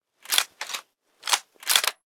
Wpn_riflehunting_reloadinout.ogg